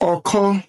Ọ̀kọ [ɔ̀kɔ́]